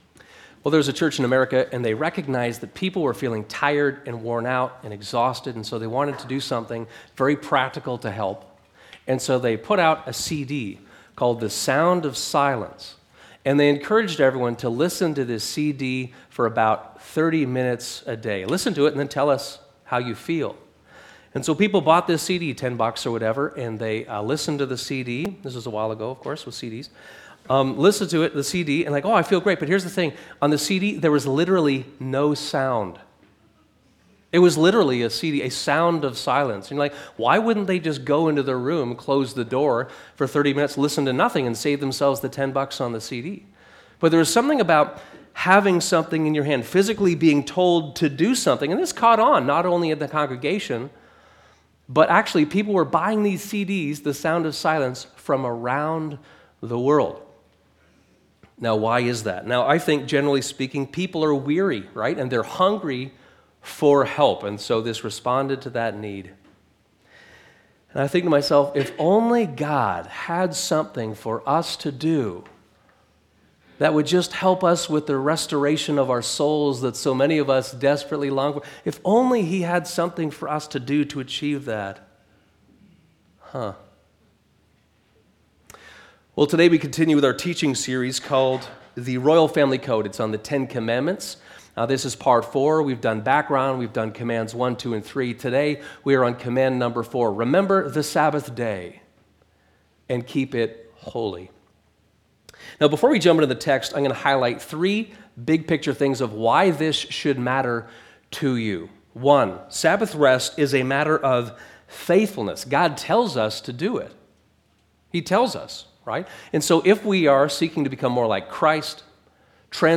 The counter-cultural restoration of Sabbath rest one day a week (Sermon)